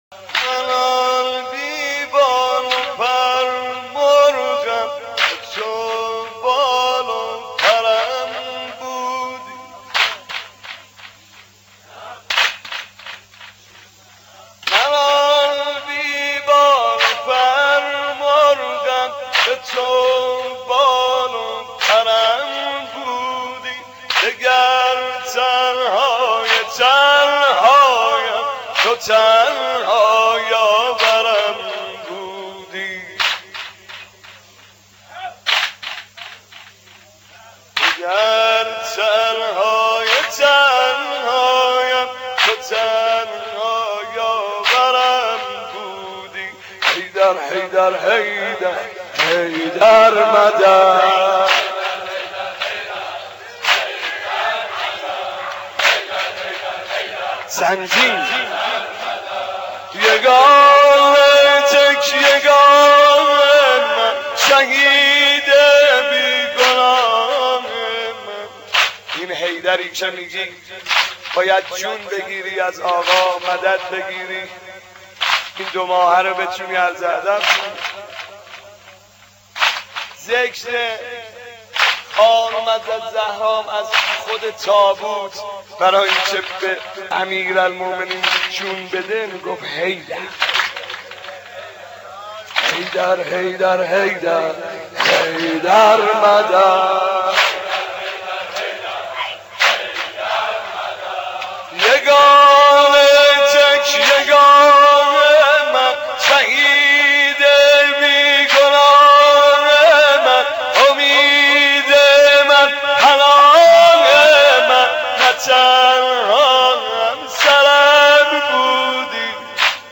دانلود مداحی در تنهایی علیع - دانلود ریمیکس و آهنگ جدید